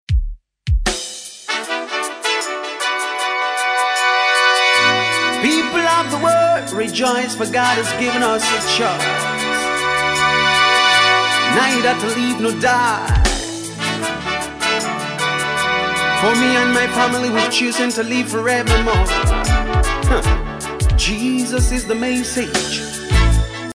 Genre: Gospel